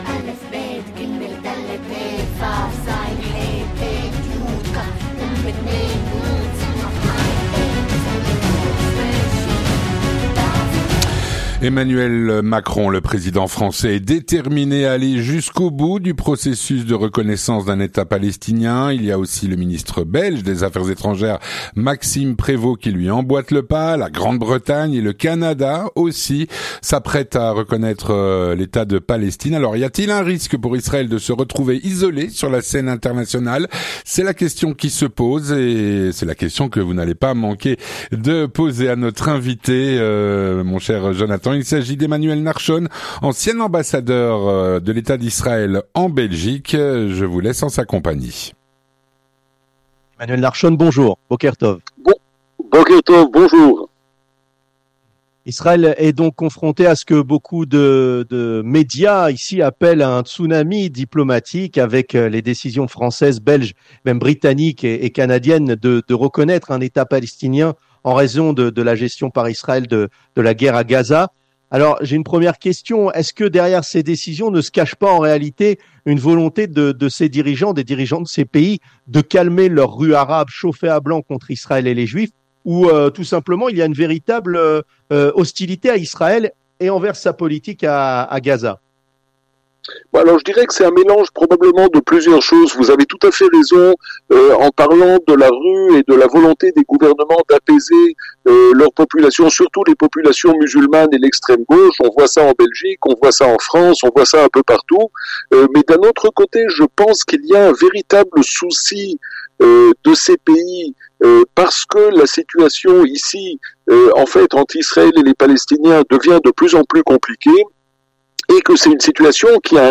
On en parle avec Emmanuel Nahshon, ancien ambassadeur de l'État d'Israël en Belgique.
Un entretien écourté en raison d'une alerte aux missiles Houtis qui a obligé notre invité et notre journaliste à se rendre aux abris.